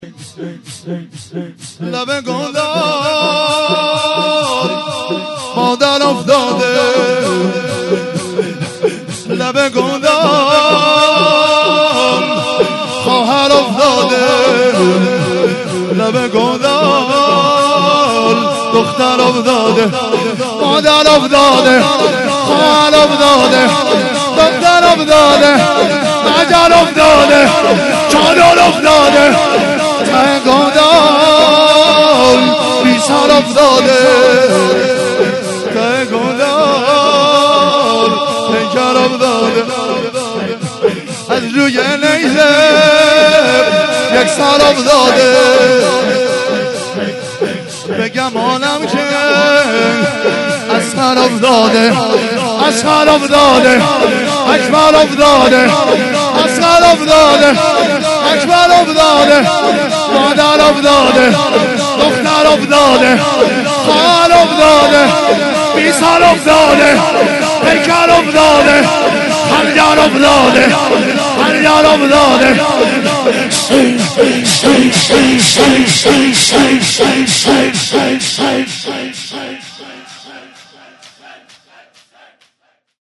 15 لب گودال مادر افتاده - شور